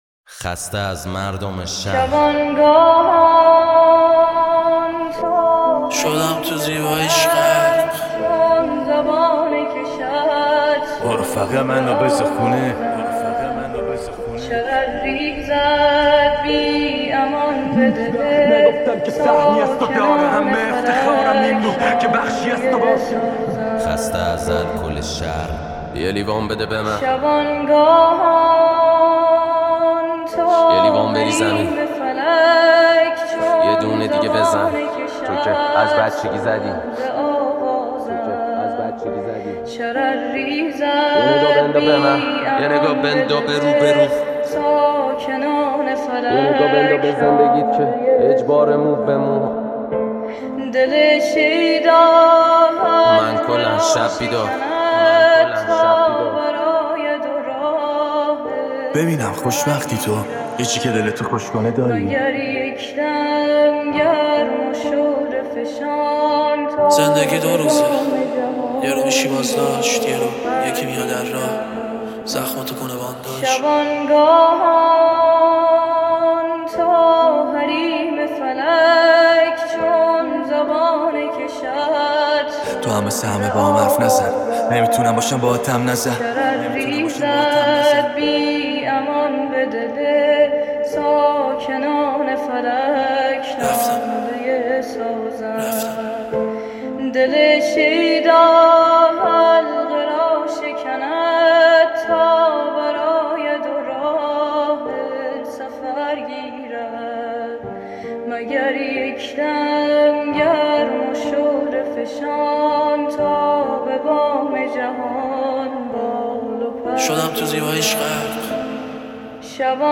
ریمیکس رپی